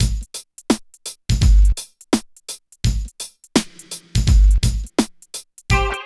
137 DRM LP-L.wav